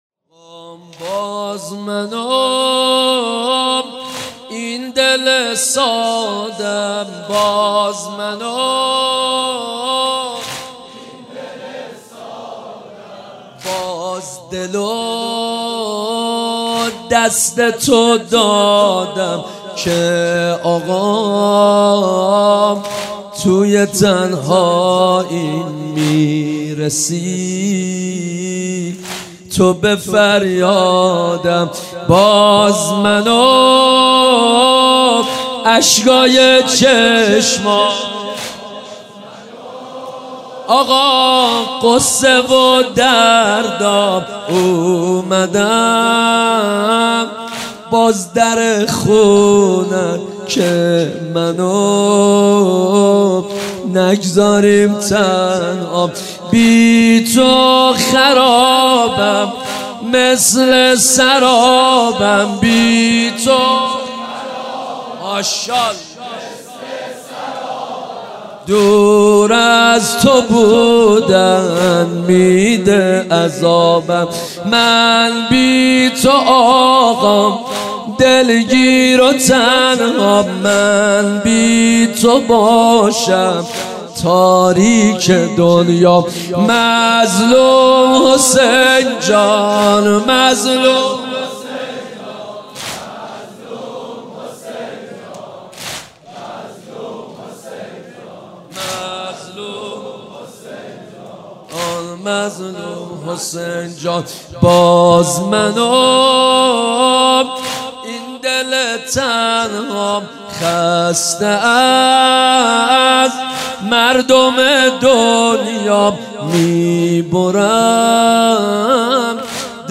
شب اول محرم الحرام 1394 | هیات مکتب الحسین اصفهان
باز من و این دل ساده م | واحد | حضرت امام حسین علیه السلام